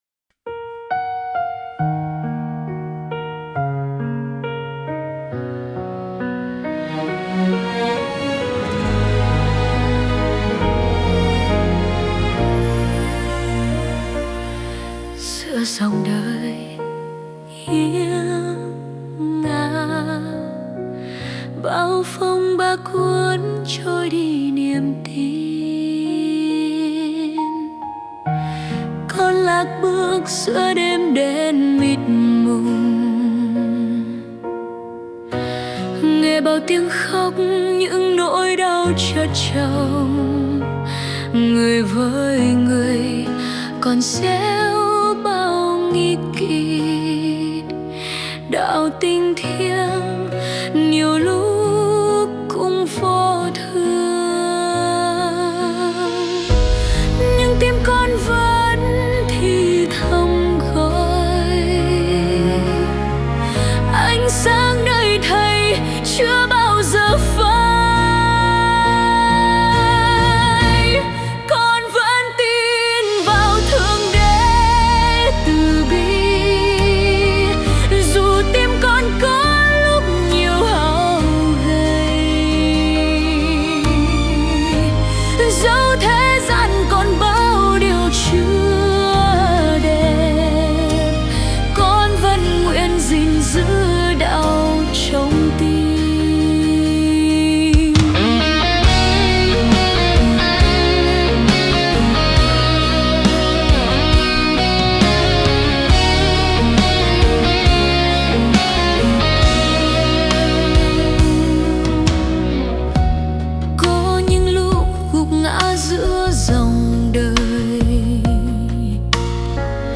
•   Vocal  01.